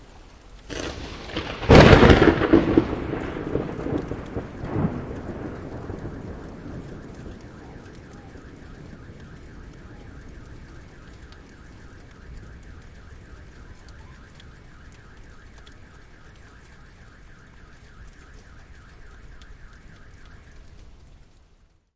轻松的雨和响亮的雷声
描述：几个雷暴的声景混合在一起，创造出独特的沉浸式全场录音。
Tag: 雷暴 风暴 天气 隆隆声 淋浴器 闪电 雷暴 氛围 环境 滚动雷 弹簧 性质 现场记录 下雨 大气